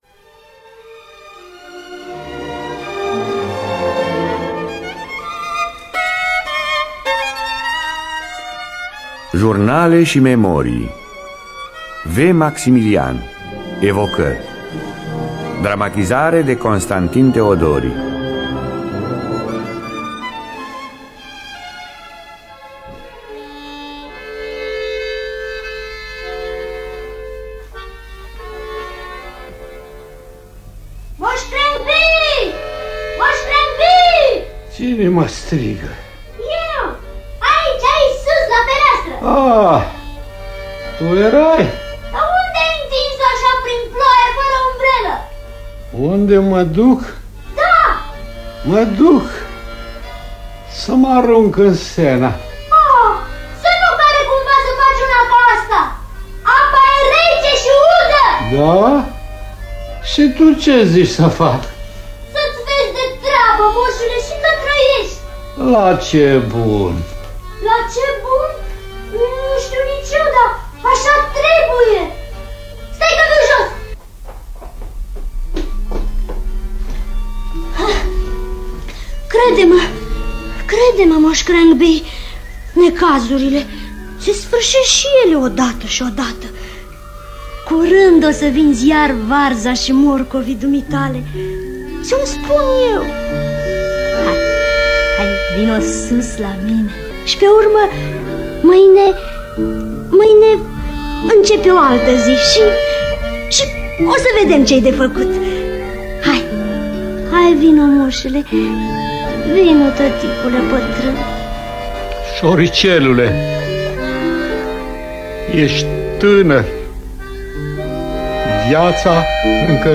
Biografii, memorii: Vladimir Maximilian - Evocări.